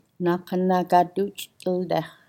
Learn-through oral teaching /du…dih/